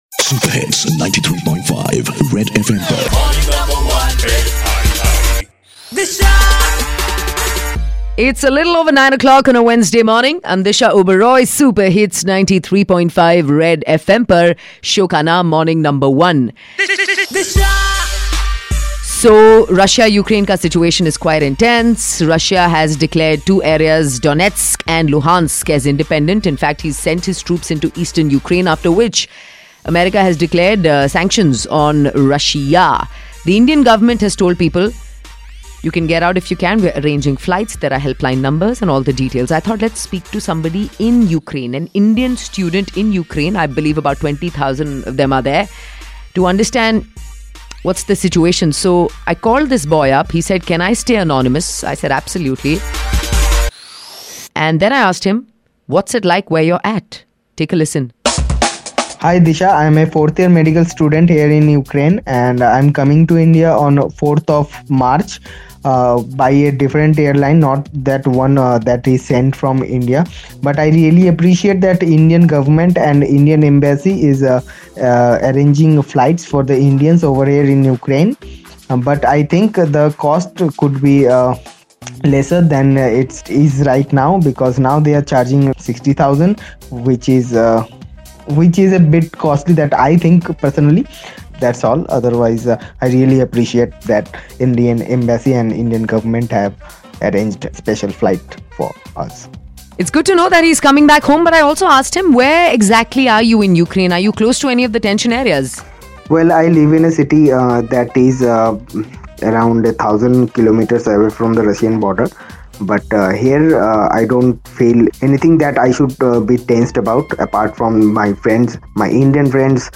Let's hear from a fellow Indian in Ukraine telling us about Russia and Ukrine conflict.